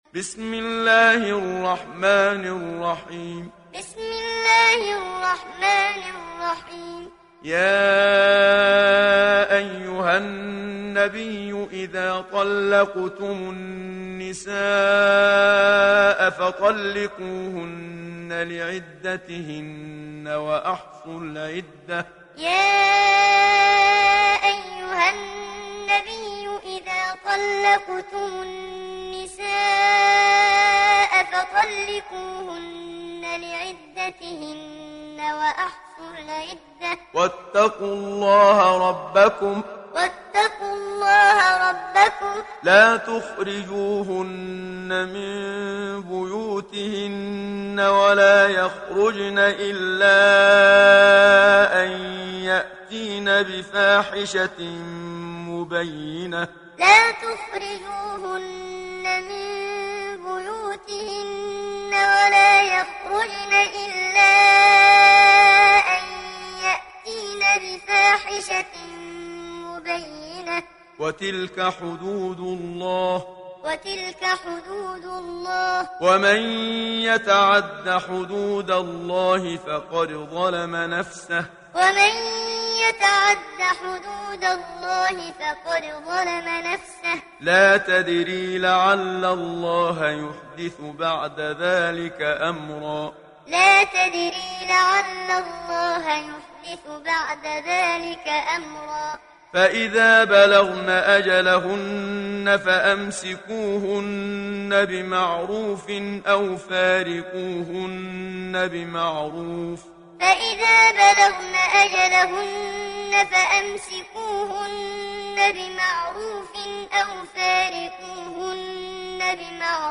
Talak Suresi İndir mp3 Muhammad Siddiq Minshawi Muallim Riwayat Hafs an Asim, Kurani indirin ve mp3 tam doğrudan bağlantılar dinle
İndir Talak Suresi Muhammad Siddiq Minshawi Muallim